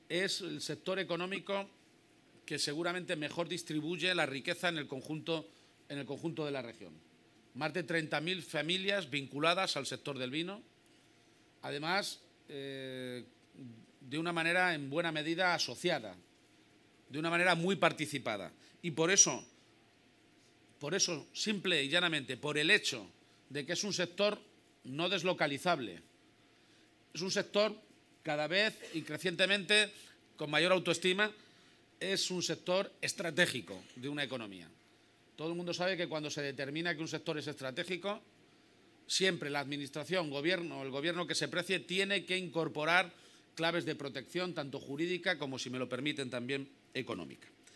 Presidente Lunes, 26 Noviembre 2018 - 7:45pm El presidente de Castilla-La Mancha ha sido reconocido esta tarde, en la localidad holandesa de Ámsterdam, con el premio ‘Voice of Wine’, en el décimo aniversario de la World Bulk Wine Exhibition (WBWE). Durante su Intervención, ha destacado el valor del sector del vino, que además de ser una seña de identidad, es el sector económico que mejor distribuye riqueza en el conjunto de la región con más de 30.000 familias vinculadas al mismo y de forma muy participada, siendo un sector no deslocalizable, que cada vez cuenta con más autoestima y siendo el sector estratégico de la economía regional. garcia-page_vino.mp3 Descargar: Descargar